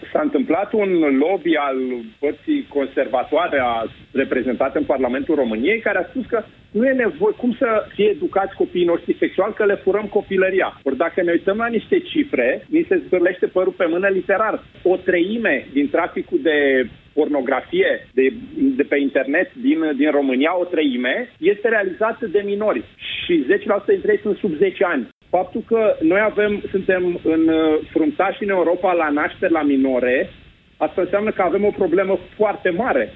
În emisiunea Piața Victoriei, Dan Barna a declarat că proiectul USR a fost sabotat de parlamentarii PSD și PNL, care au schimbat denumirea orelor în educație sanitară și au impus ca elevii să participe numai cu acordul părinților.